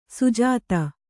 ♪ sujāta